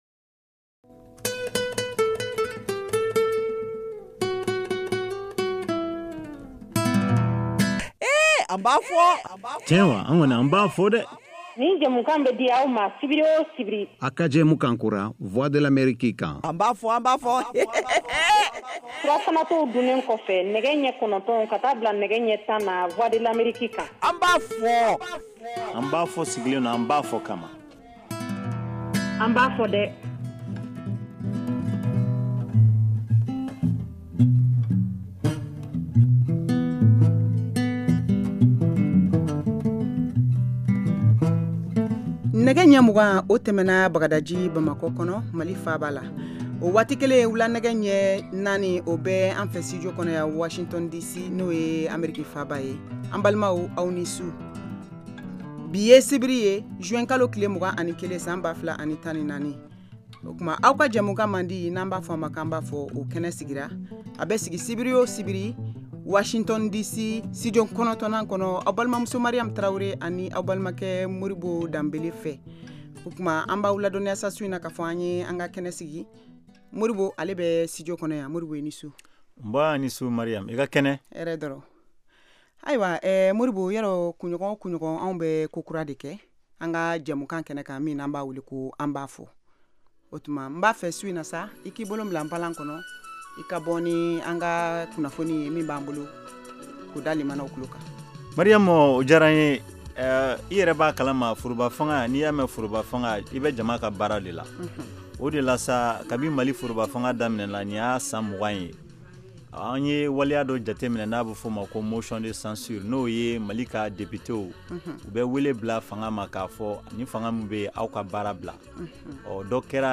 An ba fɔ! est une nouvelle émission interactive en Bambara diffusée en direct tous les samedis, de 20:00 à 21:00 T.U.